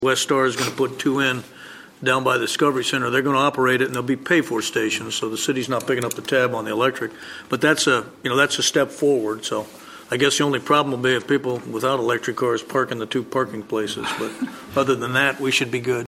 During Tuesday’s Manhattan City Commission meeting, a proposal by Westar was approved paving the way for two new user-paid electric vehicle recharging stations to be installed.
Commissioner Wynn Butler spoke favorably of the proposal.